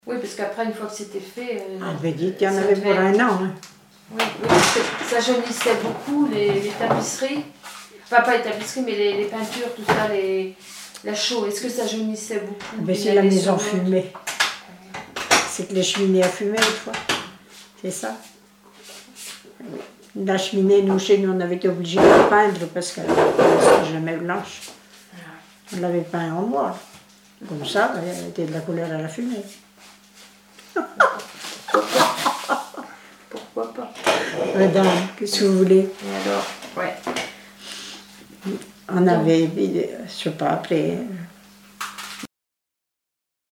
Témoignages sur les tâches ménagères
Témoignage